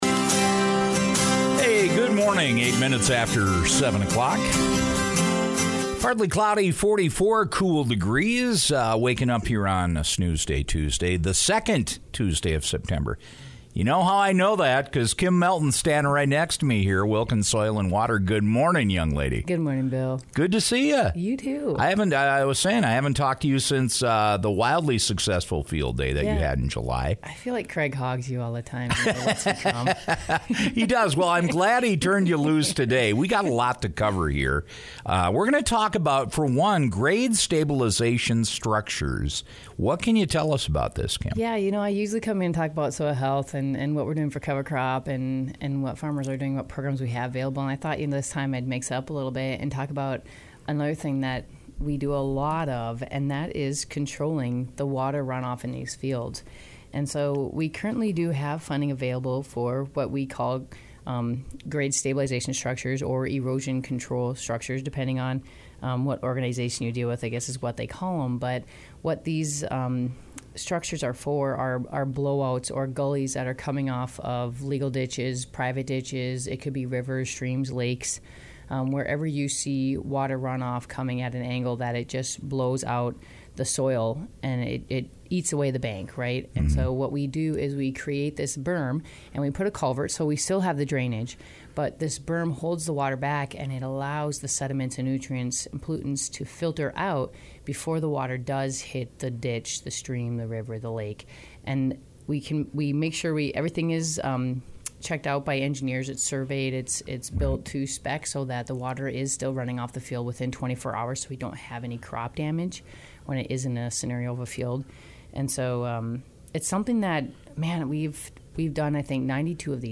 dropped by the KBMW Morning Show on Tuesday to talk about the projects and provide an update.